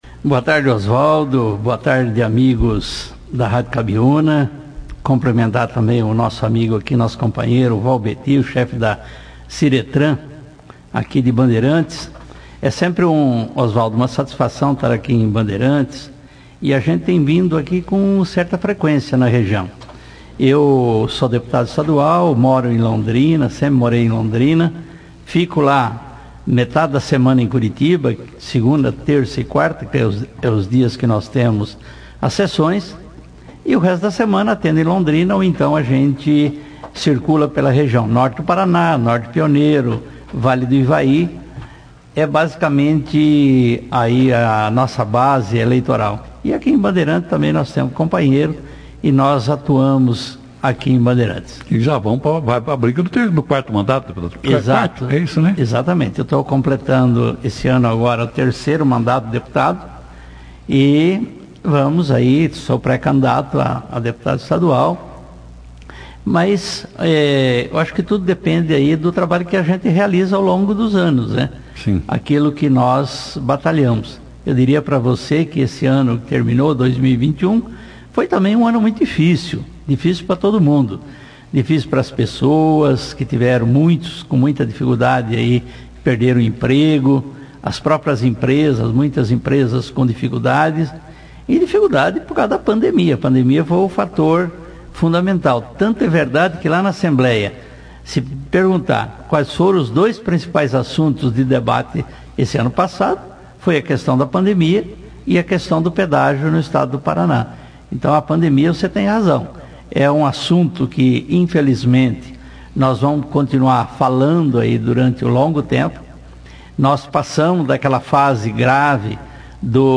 O deputado estadual Tercilio Turini, que esteve em Bandeirantes nesta terça-feira, 04/01,visitando correligionários e o ex-vereador Valbeti Palugan, e participou ao vivo da 2ª edição do jornal Operação Cidade falando falando de suas atividades parlamentares, na assembléia legislativa.